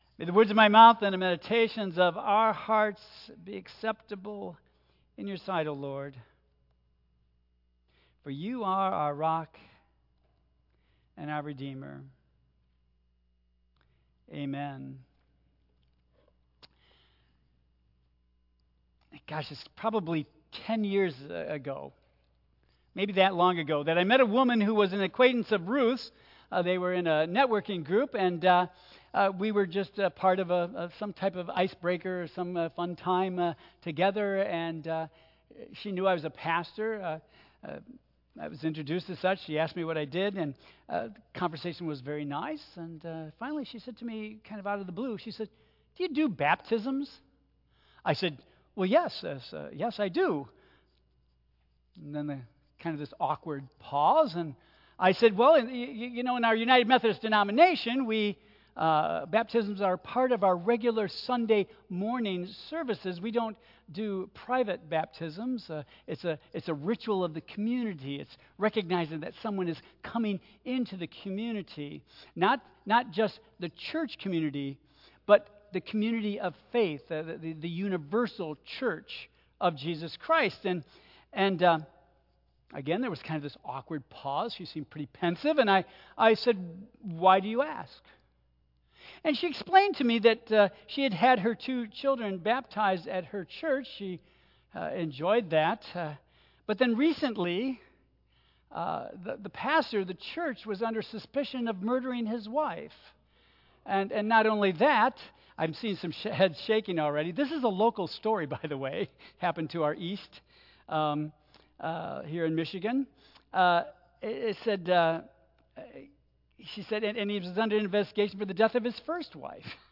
Tagged with Central United Methodist Church , Sermon , Waterford , Worship Audio (MP3) 7 MB Previous Star Light, Star Bright Next Put Your Life in DRIVE